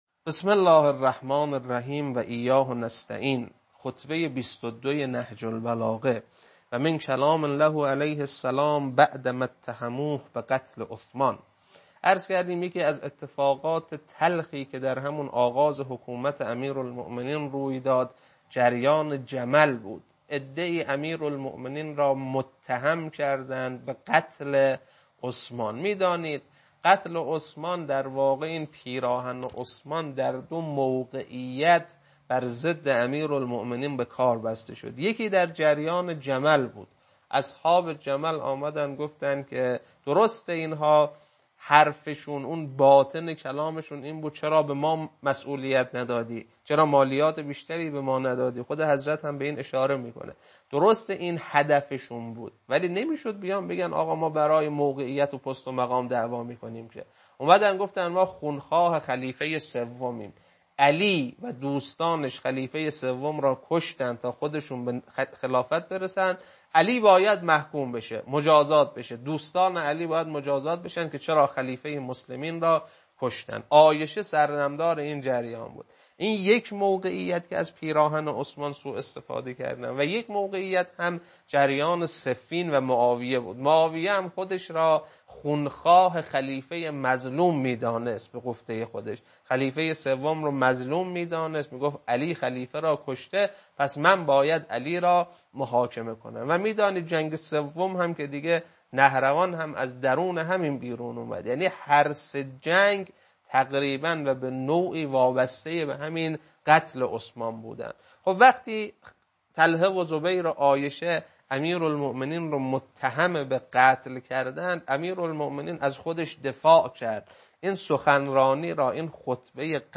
خطبه-22.mp3